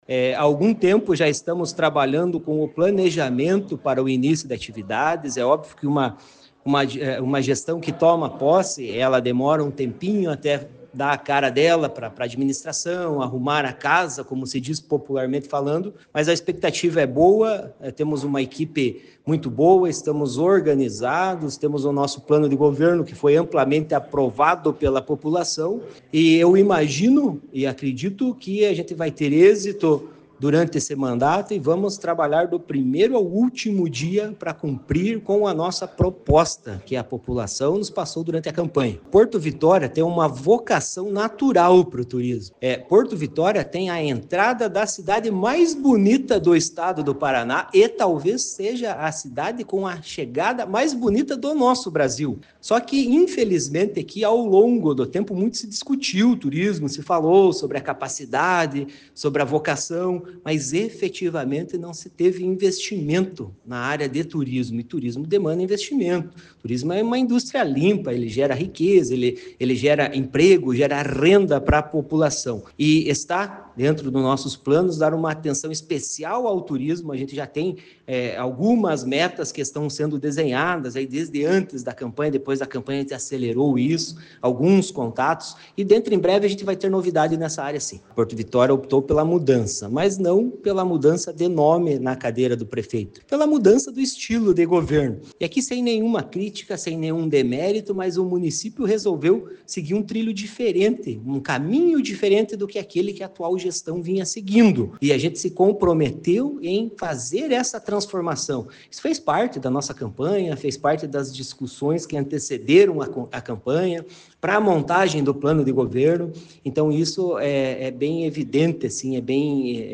esteve presente na posse e conversou com o prefeito Fabiano Glaab que falou sobre as expectativas para este início de mandato.